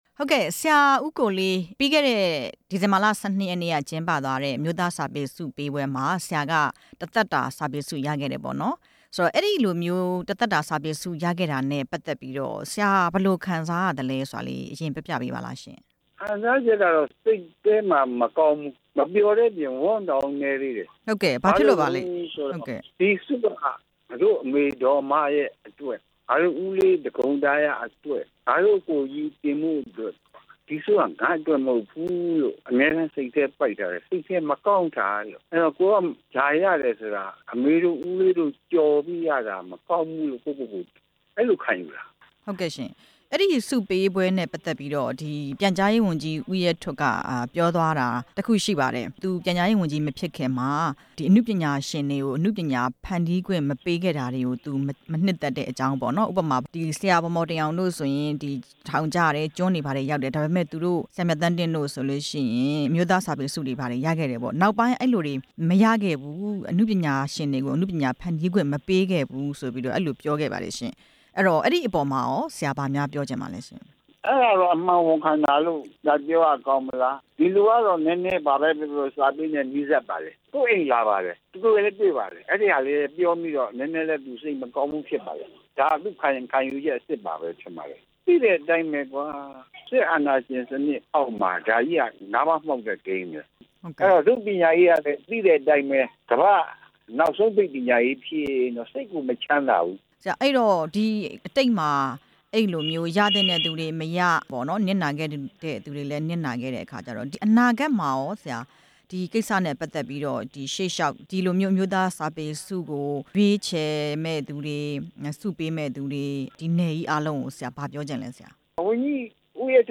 ကဗျာဆရာကြီး ကိုလေး (အင်းဝဂုဏ်ရည်) နဲ့ မေးမြန်းချက်